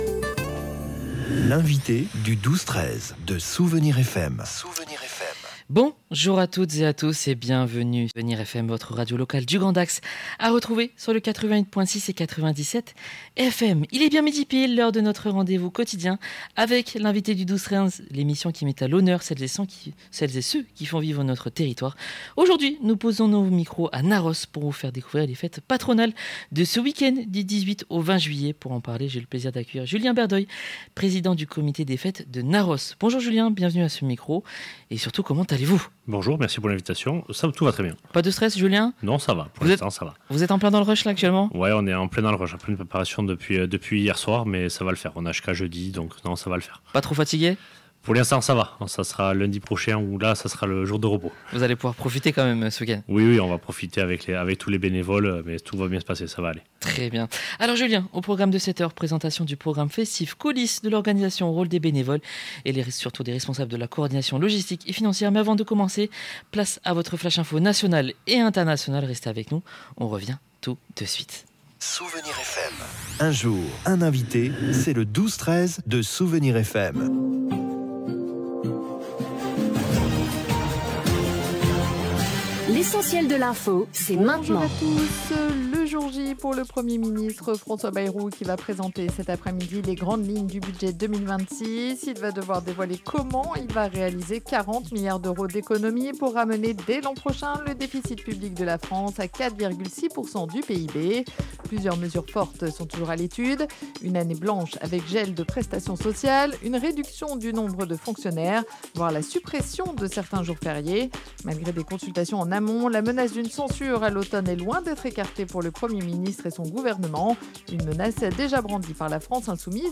Un échange nationalement chaleureux, fidèle à l’esprit de ces fêtes populaires, à réécouter sur Souvenirs FM .